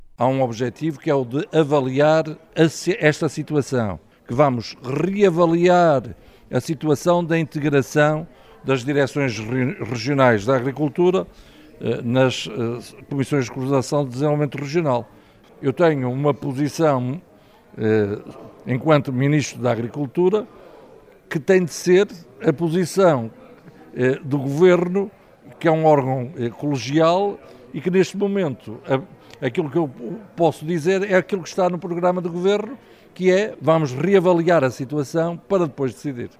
O mesmo já tinha dito, a 17 de maio, em Valpaços, no 7º congresso nacional do azeite, em declarações que agora recordamos: